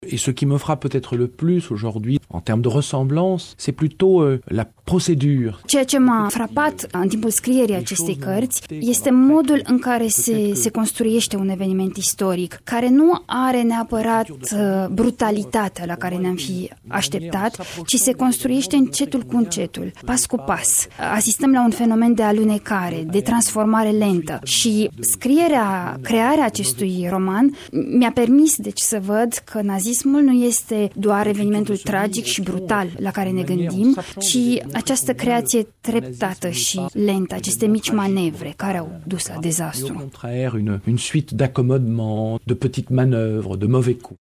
(EXCLUSIV) Laureatul Premiului Goncourt, Eric Vuillard, prezent în studioul Radio Iaşi